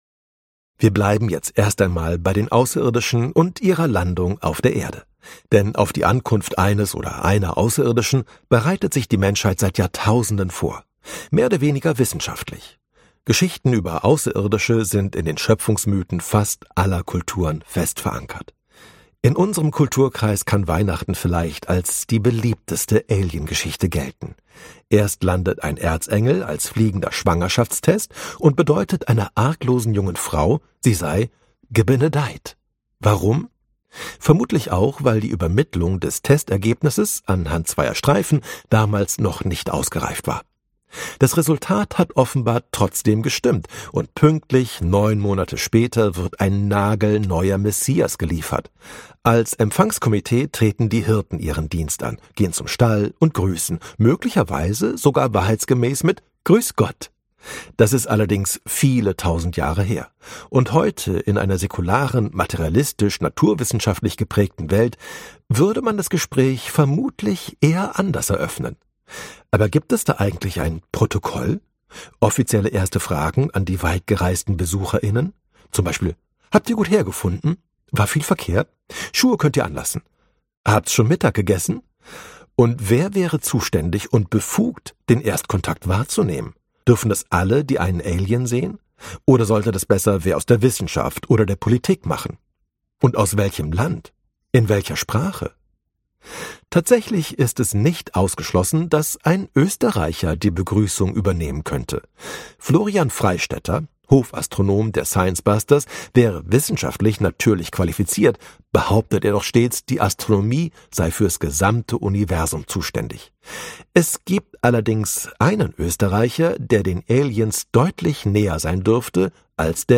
Die Wissenschaft vom Ende: Warum manche Dinge krachen gehen und andere einfach nicht aufhören wollen - gelesen von Ralph Caspers